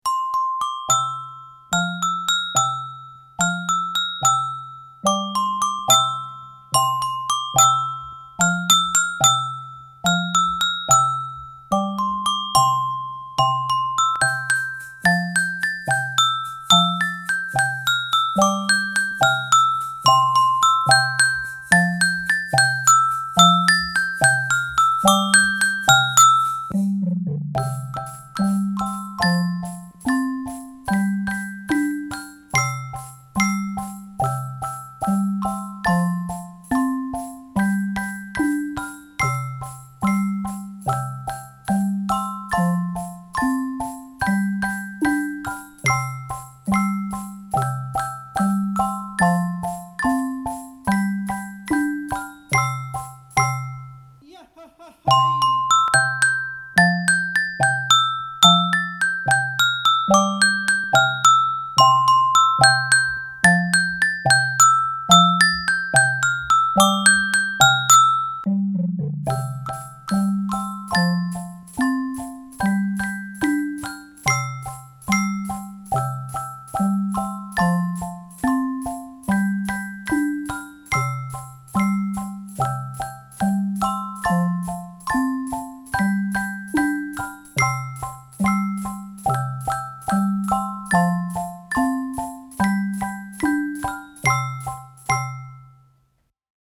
Os instrumentos Orff
Imos practicar esta canción mexicana co instrumental Orff.
• Metalófono soprano
• Xilófono
• Xilófono baixo
• Axóuxeres